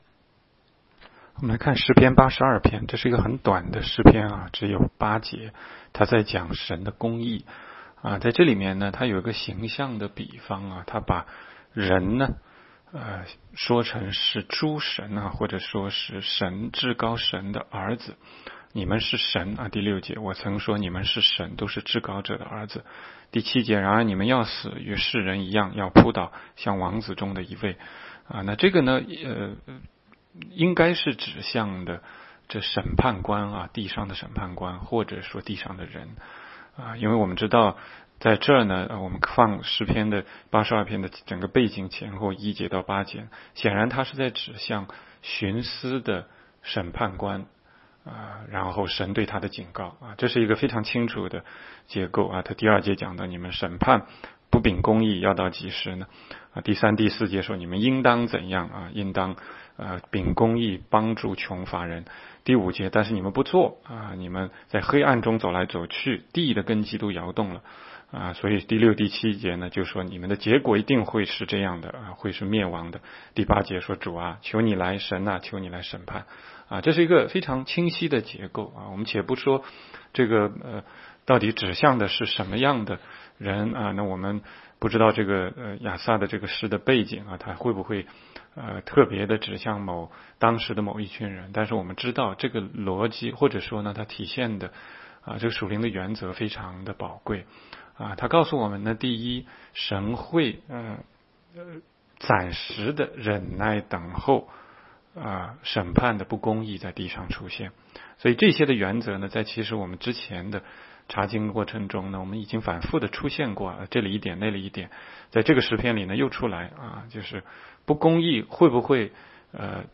16街讲道录音 - 每日读经-《诗篇》82章